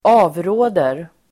Uttal: [²'a:vrå:der]